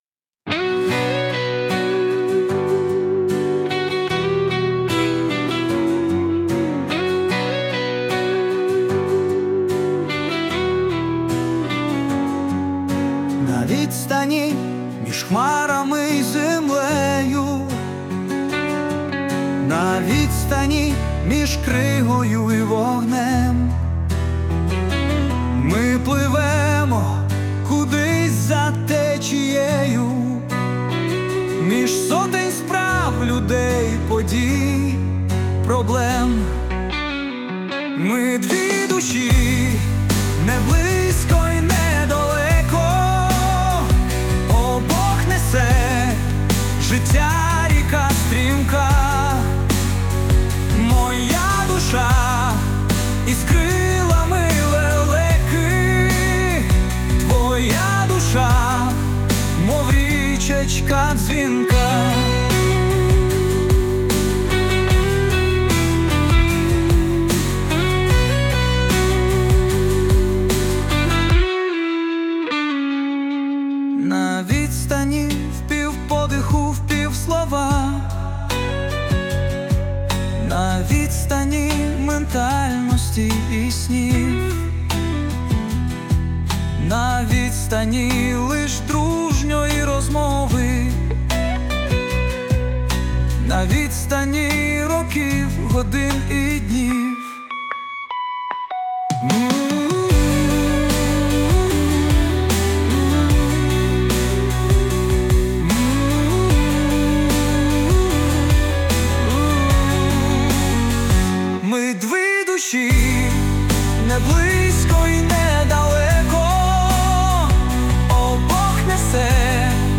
12 Чудова лірика! 16 І прекрасно звучить в пісенному варіанті! tender
Тепер це ще й пісня. give_rose hi
Мелодійно ніжно лірично1639